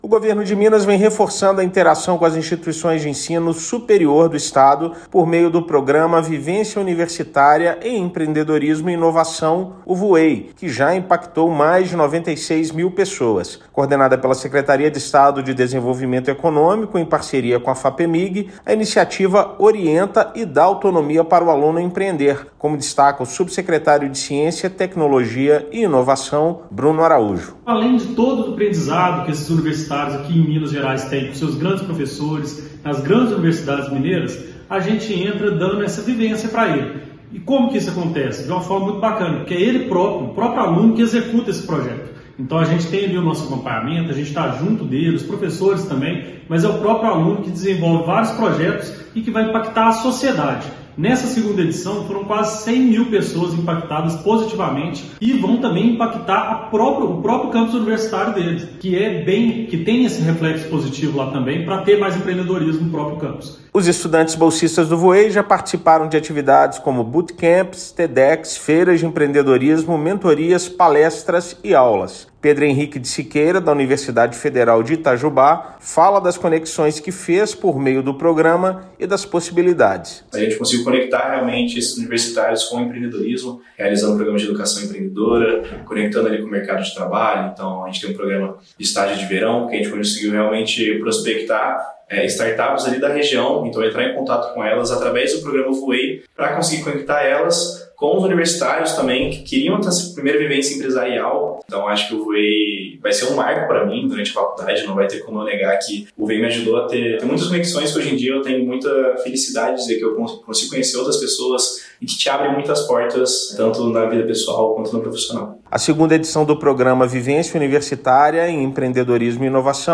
[RÁDIO] Governo de Minas fortalece inovação nas universidades e impacta mais de 96 mil pessoas com programa de empreendedorismo
Programa Vivência Universitária em Empreendedorismo e Inovação (Vuei) já promoveu 800 ações, com investimentos de R$ 2,7 milhões. Os resultados impulsionam participação de professores e alunos. Ouça matéria de rádio.